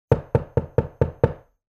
Tocan la puerta